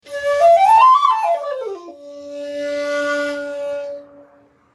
Shakuhachi 61